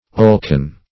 Search Result for " oilcan" : Wordnet 3.0 NOUN (1) 1. a can with a long nozzle to apply oil to machinery ; The Collaborative International Dictionary of English v.0.48: oilcan \oil"can`\, n. A small can with a long spout used to apply oil to machinery, for purposes of lubrication.